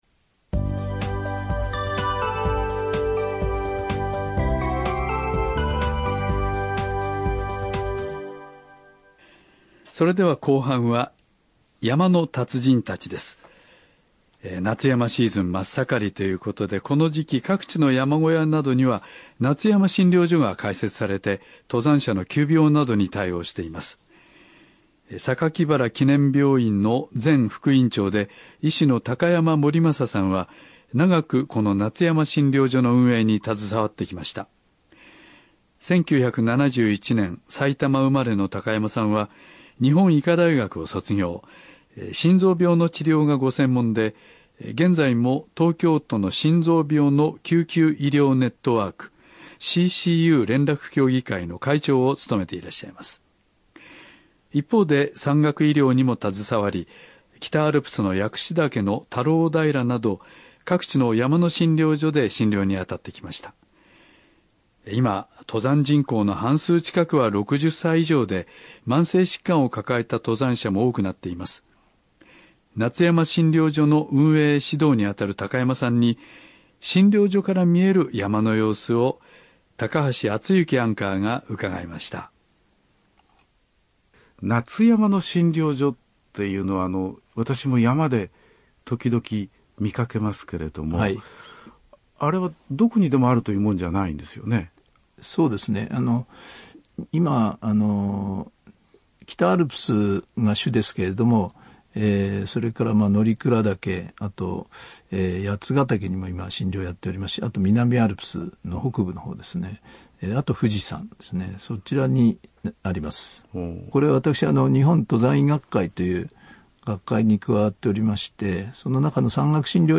ラジオ講演「山の診療所のプロ」